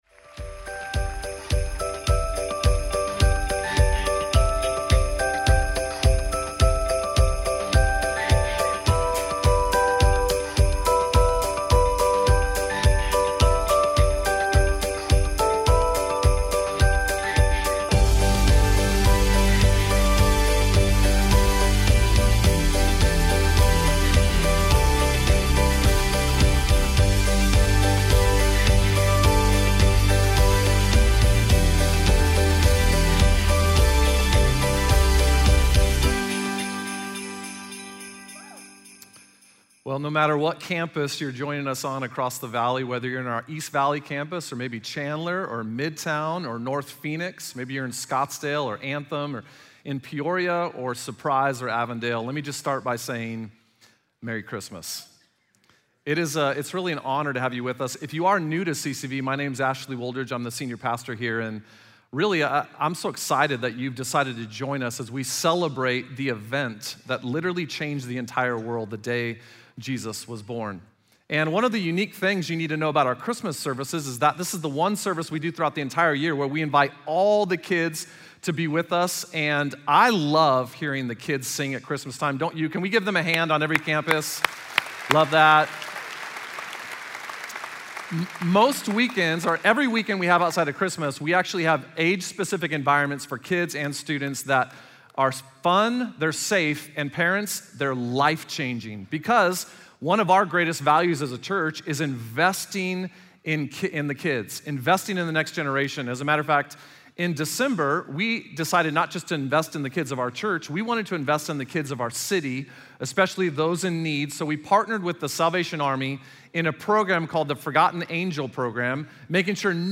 Message Only Full Service A Pixel Perfect Christmas Multiple Speakers Dec 20, 2018 CCV would like to wish you and your family a merry Christmas!